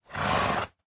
sounds / mob / horse / idle1.ogg